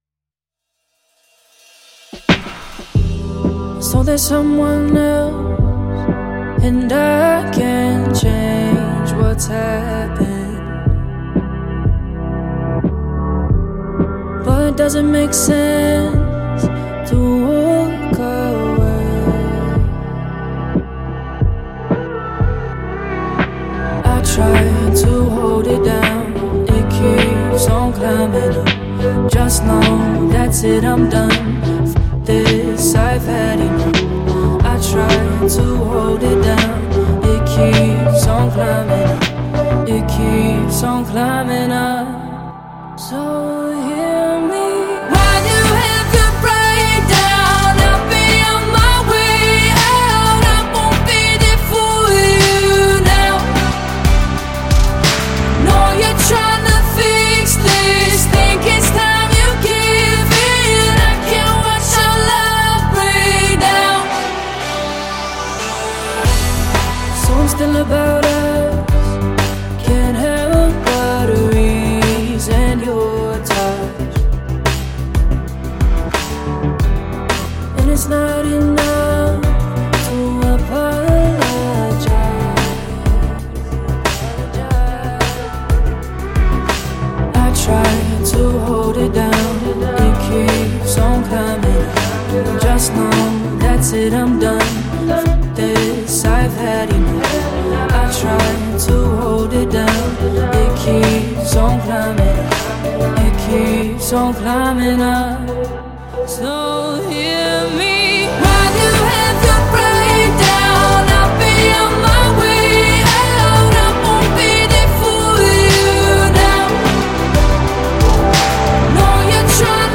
# pop # Singer-Songwriter